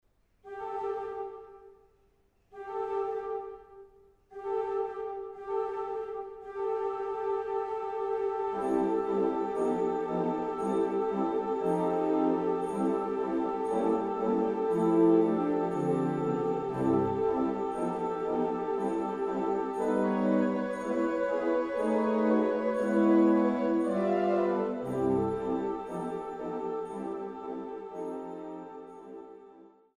Oberlinger Orgel der Stadtkirche Dillenburg
Zwölf Orgelstücke